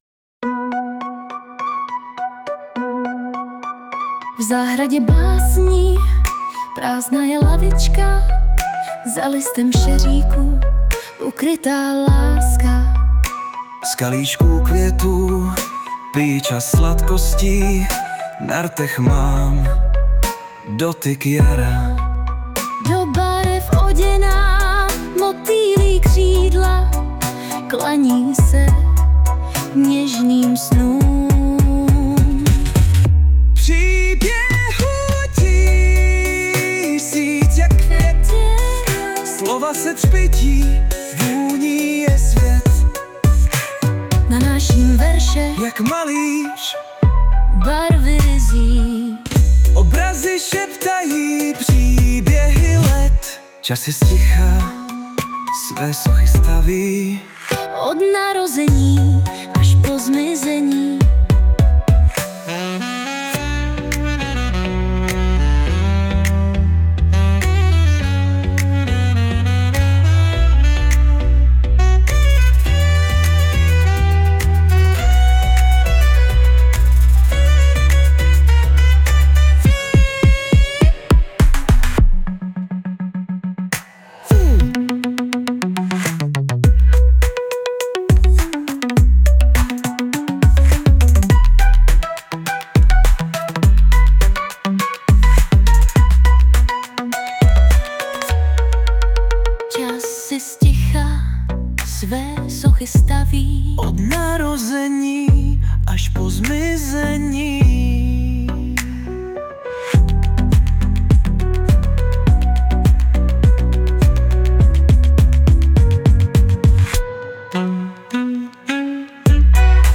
2025 & Hudba, Zpěv a Obrázek: AI